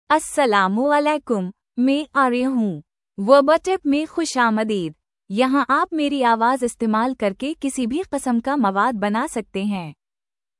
Aria — Female Urdu (India) AI Voice | TTS, Voice Cloning & Video | Verbatik AI
Aria is a female AI voice for Urdu (India).
Voice sample
Listen to Aria's female Urdu voice.
Aria delivers clear pronunciation with authentic India Urdu intonation, making your content sound professionally produced.